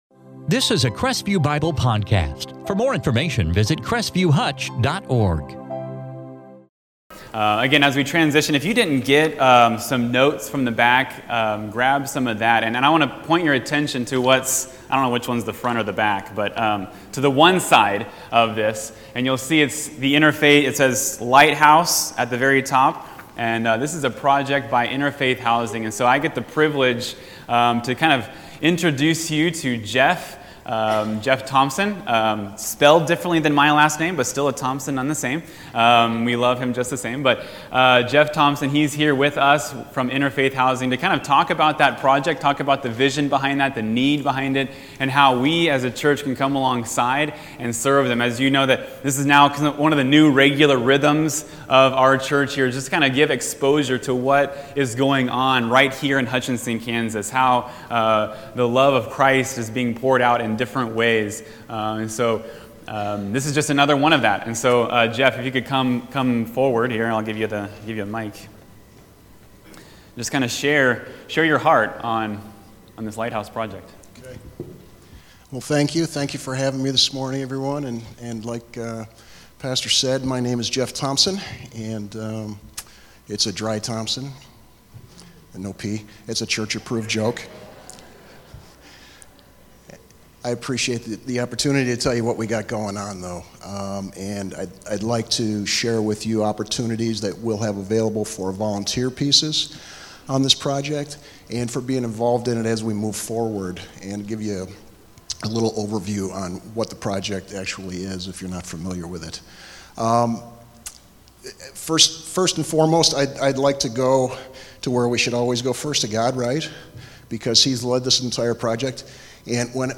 In this sermon, we begin with a report from Interfaith Housing on the Lighthouse Project and hear of the Lord’s Compassion that invites us into the need.